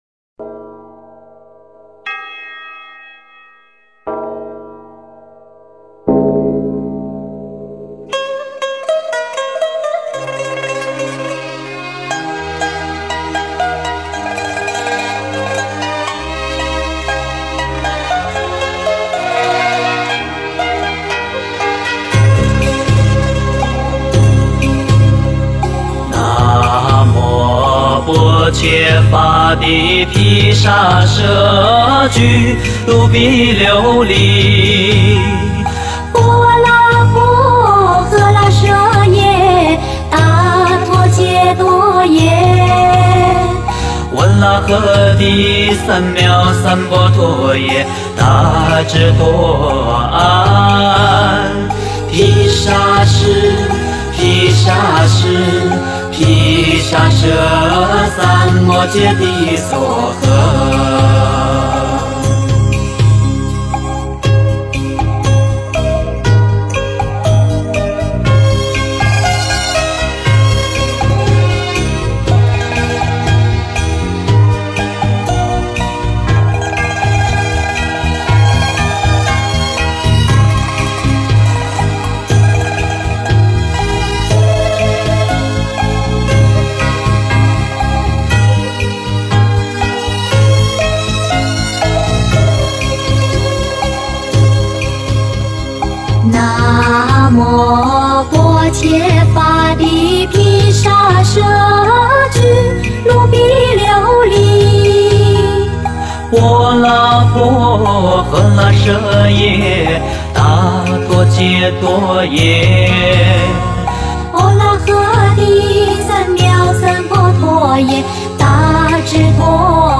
佛音 诵经 佛教音乐 返回列表 上一篇： 观音普门品 下一篇： 发殊胜心 相关文章 弥勒救苦真经(国语唱颂版